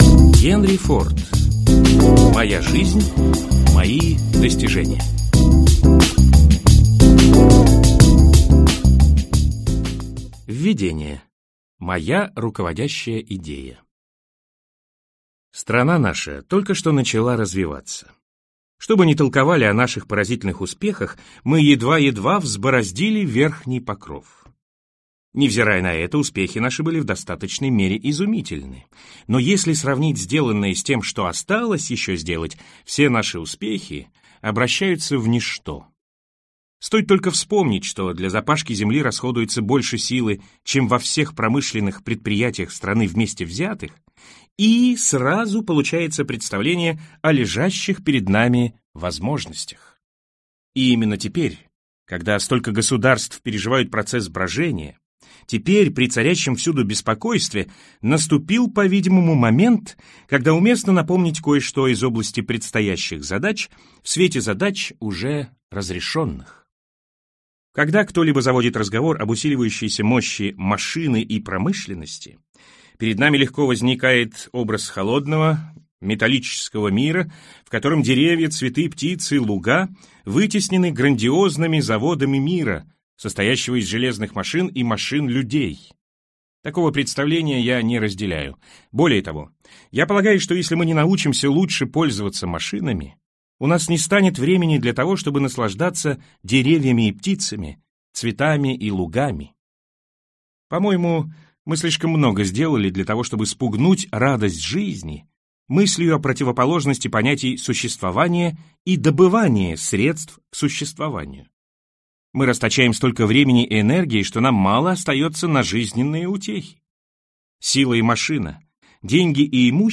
Аудиокнига Моя жизнь. Мои достижения | Библиотека аудиокниг